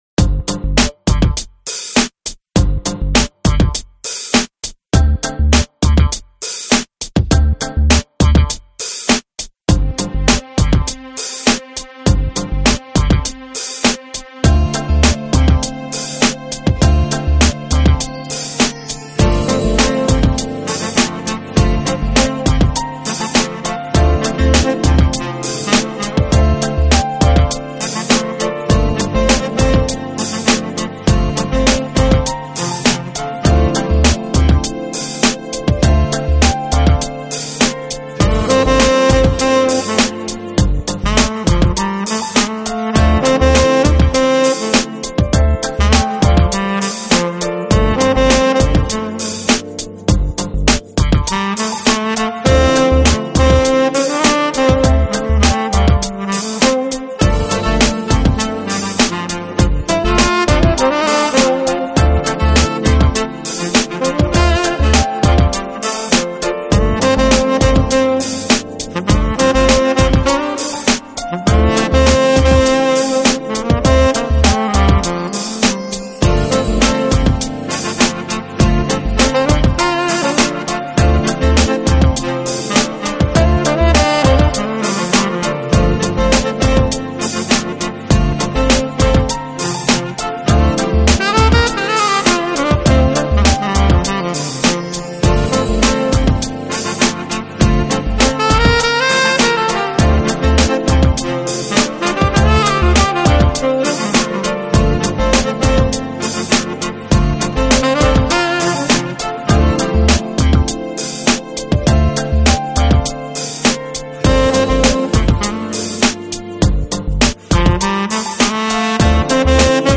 Genre: Jazz / Smooth Jazz